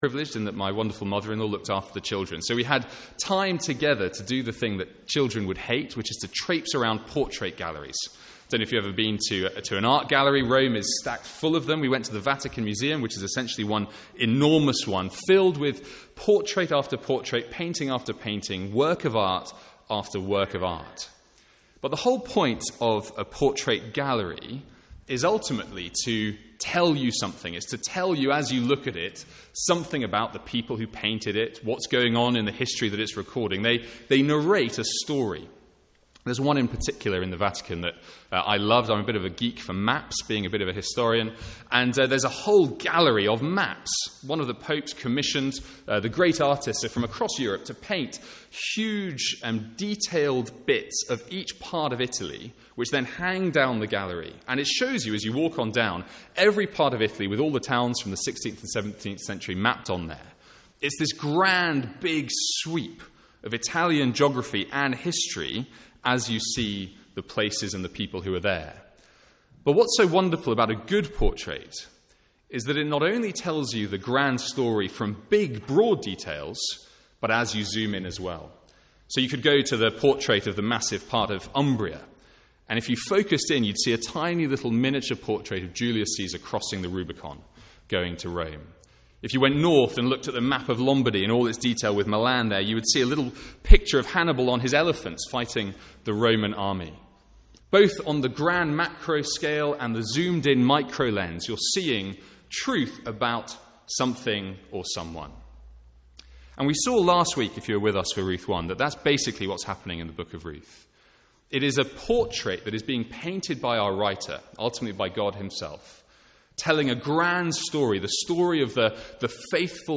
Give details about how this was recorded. From the Sunday morning series in Ruth. (First minute missing from recording).